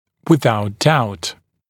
[wɪ’ðaut daut][уи’заут даут]без сомнений, несомненно